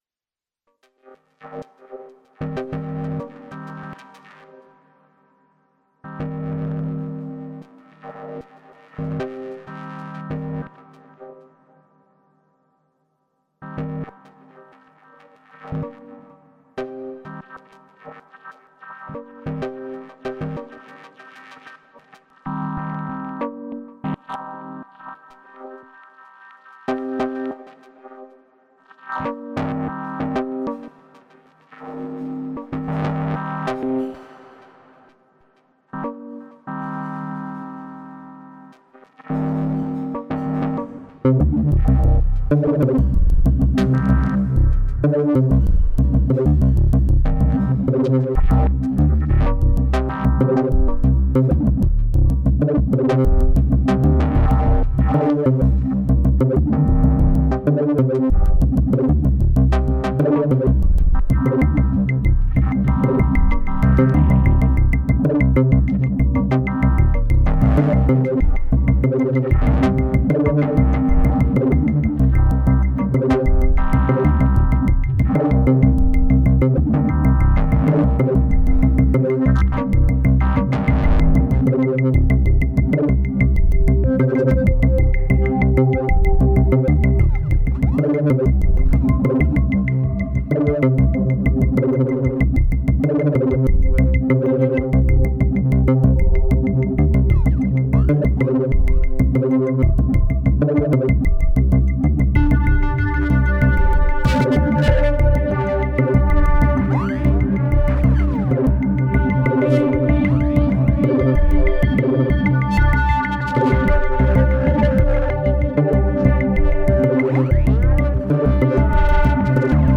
8 tracks of SY CHORD, the rather flaccid kick now overwhelmed by the bass that now dominates and was the last element to be added. Two pages of FX Block, with LFO locks on page 2 to vary the effect.
(Edit: maybe I was irritated because my kick sounded so lame)